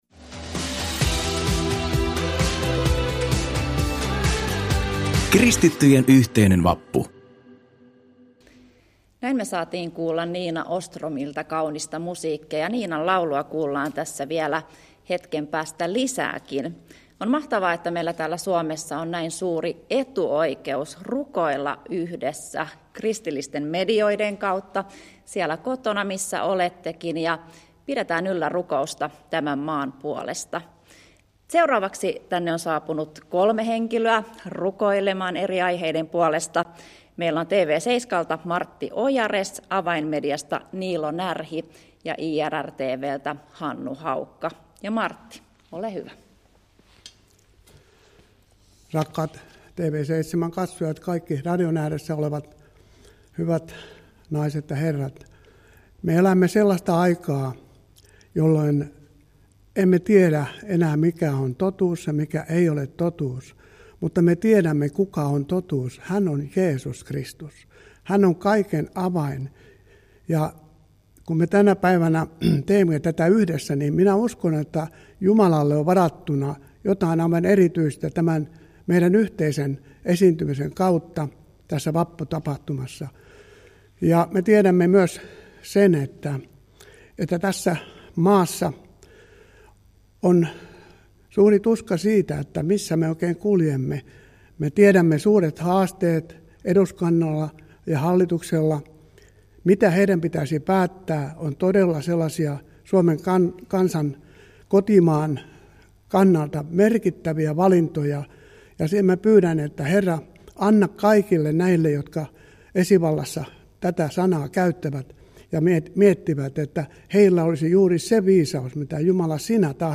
Kristittyjen yhteinen vappu – tapahtuma toteutettiin tänä vuonna ainutlaatuisena yhteislähetyksenä sähköisissä kristillisissä medioissa. Tapahtuman teemana oli Jeesus – Toivoa tulevaisuuteen.
Suomen puolesta rukoilivat medioiden päätoimittajat ja vierailijat eri paikkakunnilta.